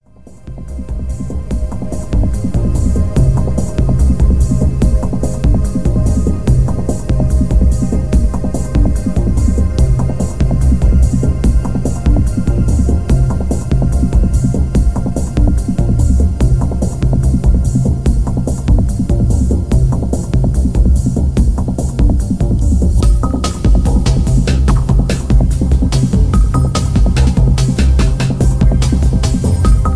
Ambient DnB remix
Tags: dnb